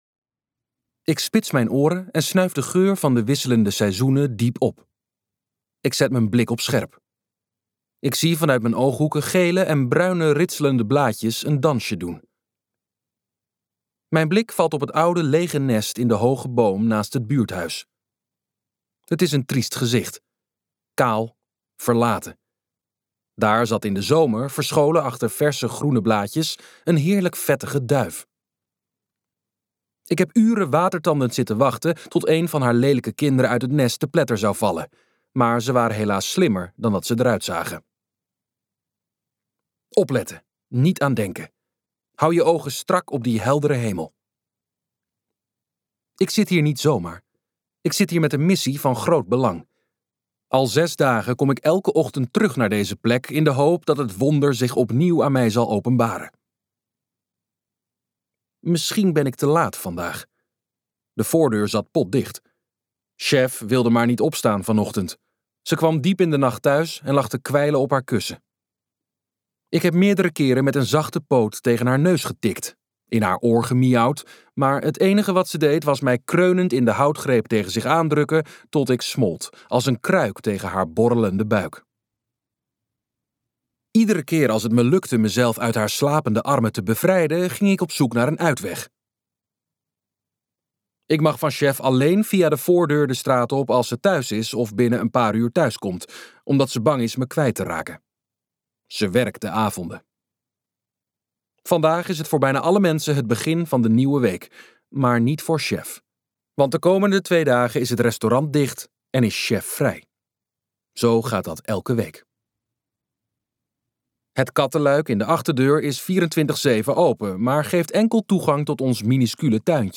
Meneertje luisterboek | Ambo|Anthos Uitgevers